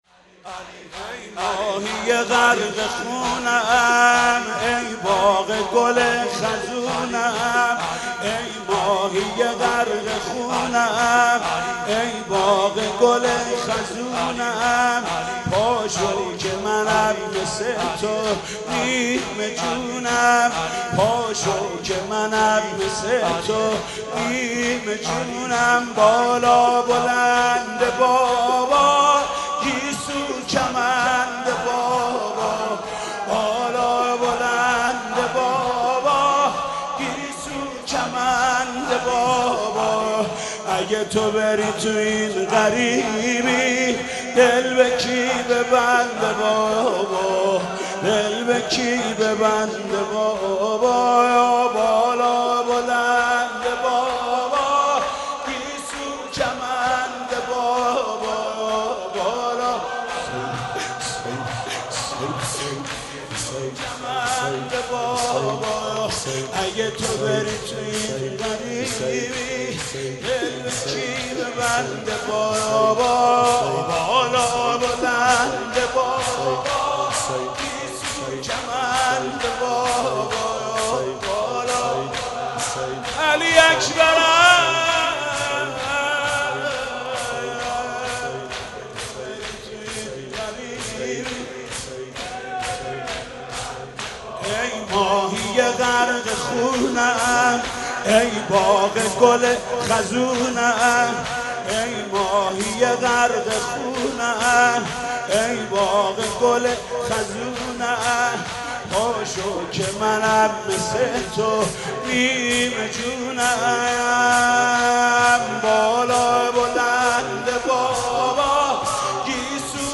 دسته بندی :صوت , مدیحه سرایی , مذهبی , مرثیه سرایی